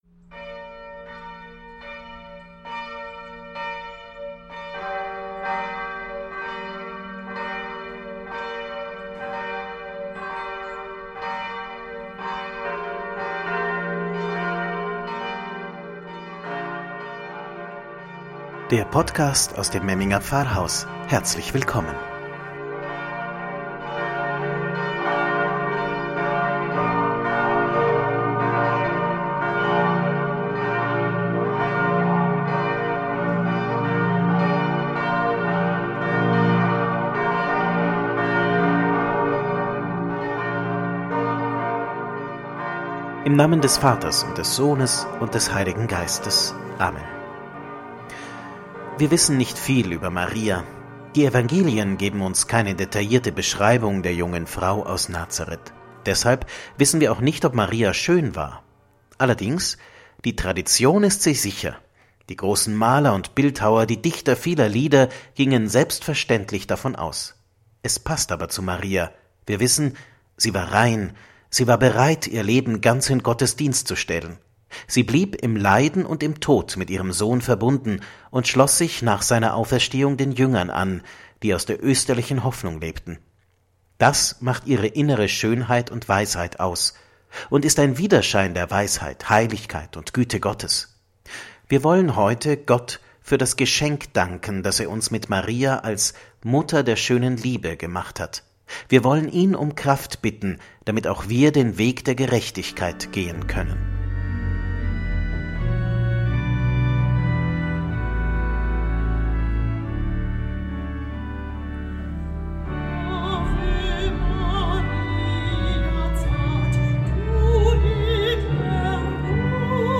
Einige Gedanken und Gebete mit Maria, Marienlieder und den Blick über den eigenen Tellerrand hinaus.
Maiandacht_To_Go_2020.mp3